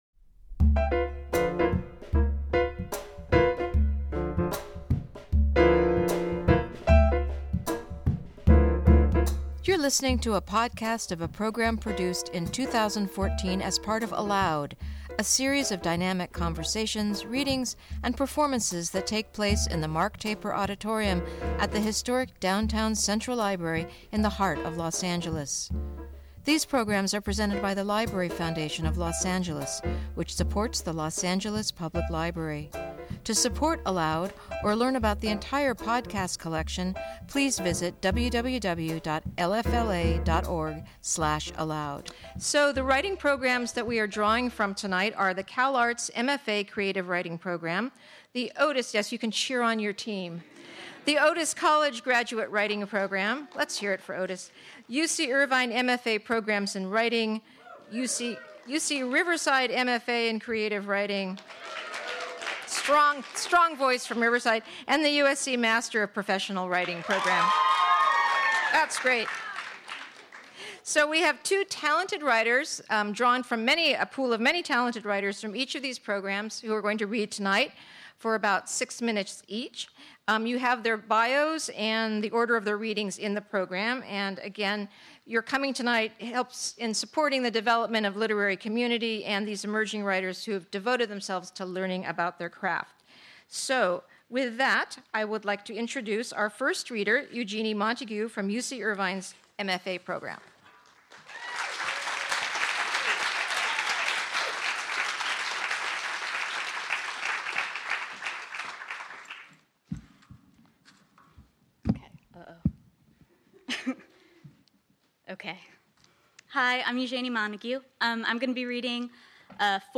Writing Our Future: Readings from Graduate Writing Programs of the Southland
Join students from five Southland graduate writing programs—CalArts, Otis College, UC Irvine, UC Riverside, and USC—as they share recent writings and tune our ears to the future of language.